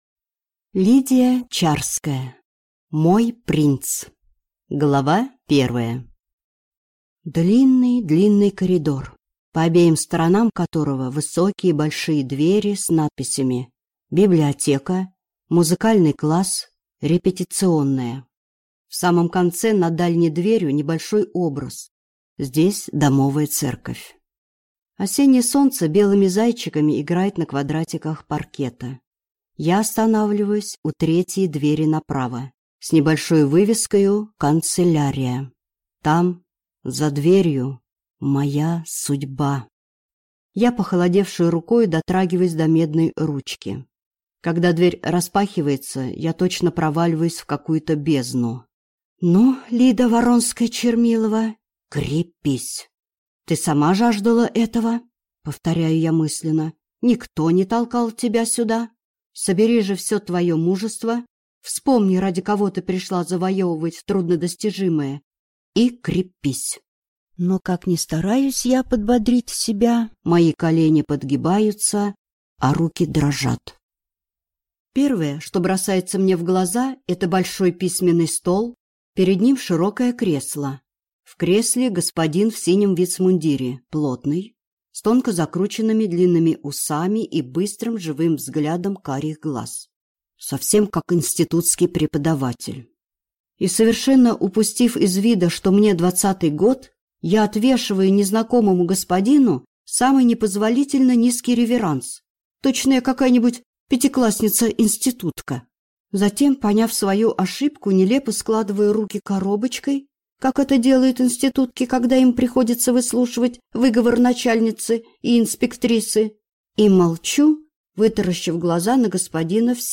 Аудиокнига Мой принц | Библиотека аудиокниг